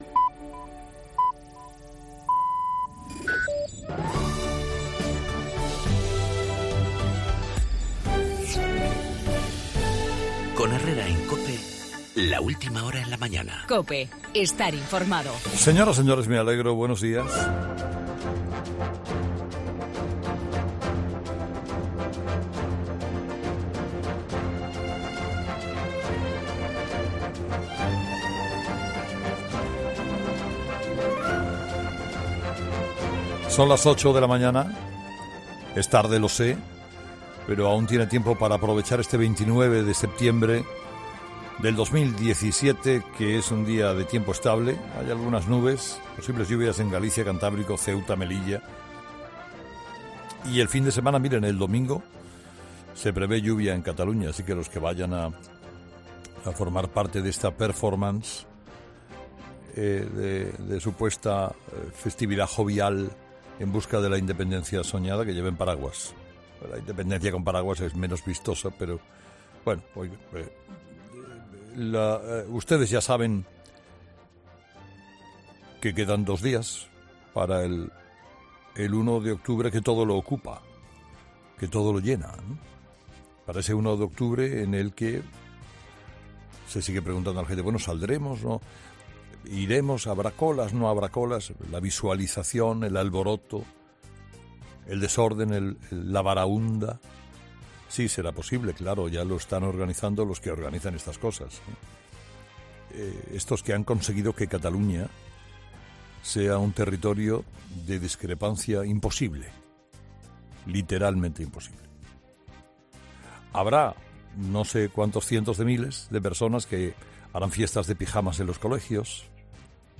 Crecen las tensiones políticas en España por el referéndum de Cataluña, en el editorial de Carlos Herrera